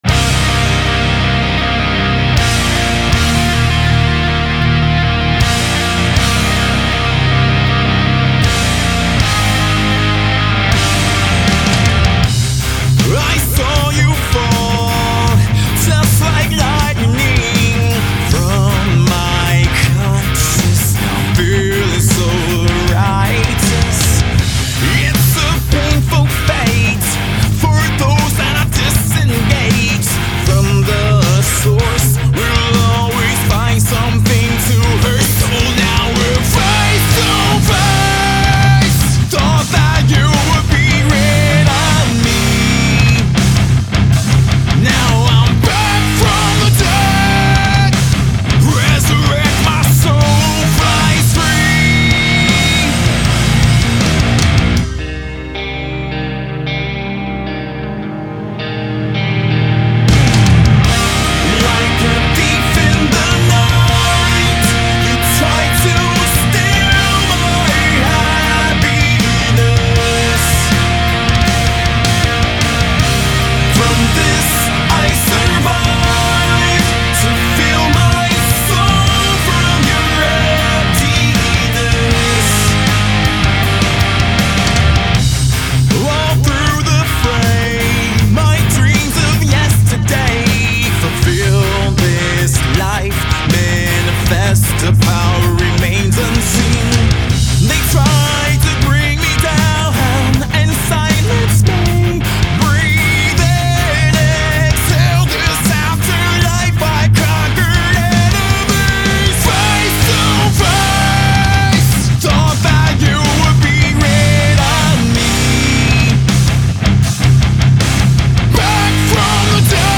Genre: Modern Rock.